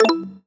dialog-dangerous-select.wav